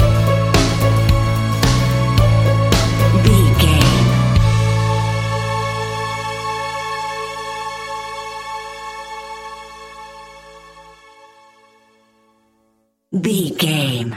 Ionian/Major
calm
melancholic
smooth
soft
uplifting
electric guitar
bass guitar
drums
strings
pop rock
indie pop
organ